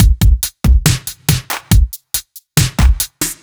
Index of /musicradar/french-house-chillout-samples/140bpm/Beats
FHC_BeatA_140-01.wav